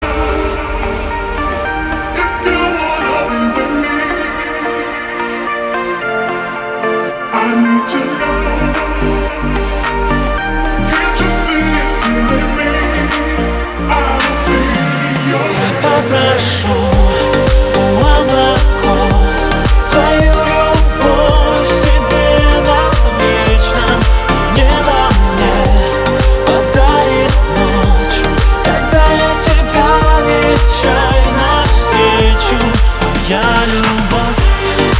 • Качество: 320, Stereo
поп
мужской вокал